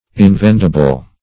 invendible - definition of invendible - synonyms, pronunciation, spelling from Free Dictionary
Search Result for " invendible" : The Collaborative International Dictionary of English v.0.48: Invendible \In*vend"i*ble\, a. [L. invendibilis.